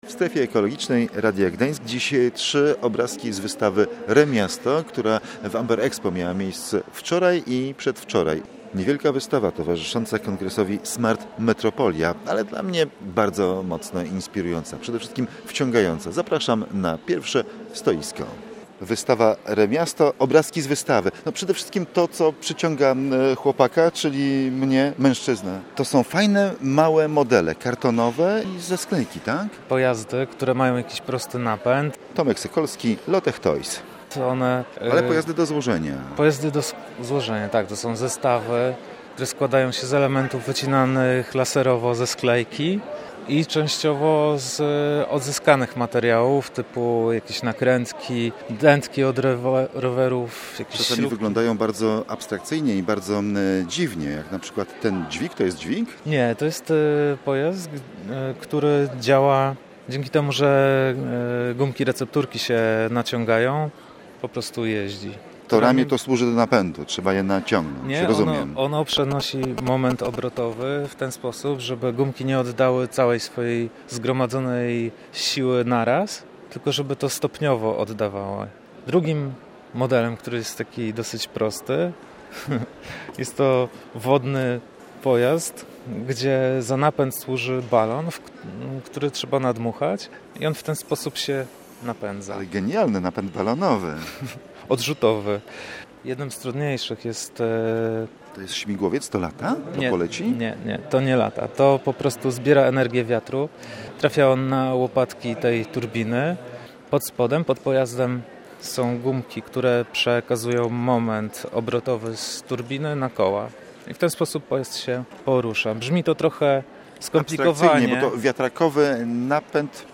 W audycji przedstawiamy trójkę fanów recyklingu.